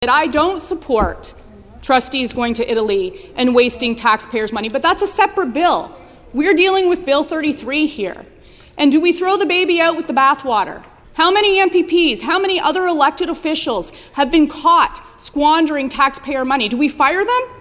Brady was quick to respond.